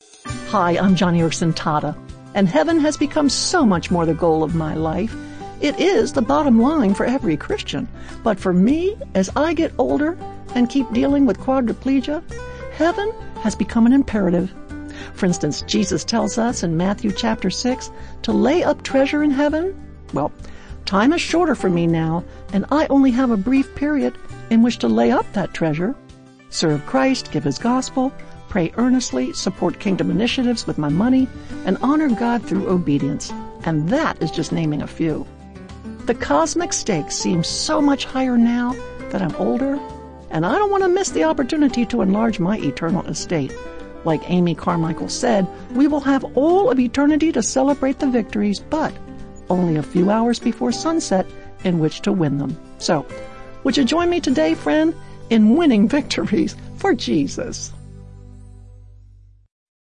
By |Published On: November 9, 2019|Categories: 1-Minute Radio Program|